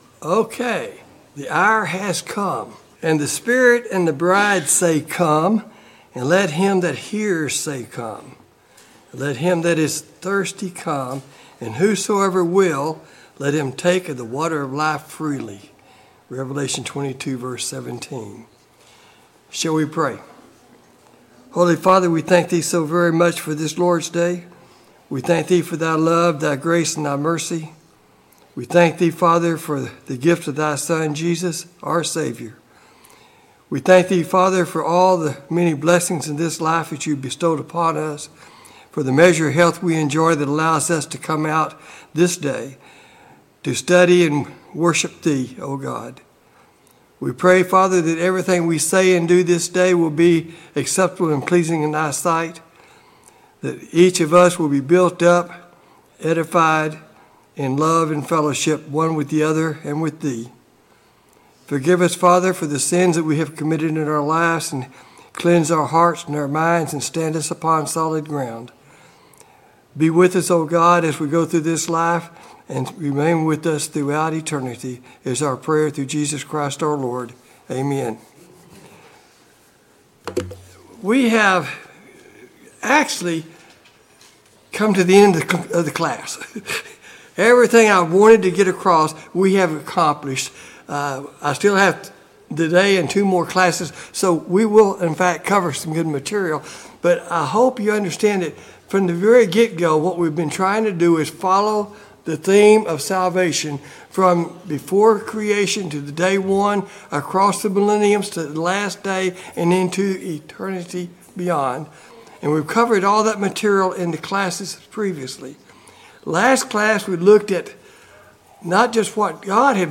God's Scheme of Redemption Service Type: Sunday Morning Bible Class « Study of Paul’s Minor Epistles